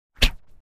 sword-gesture3
Tags: sword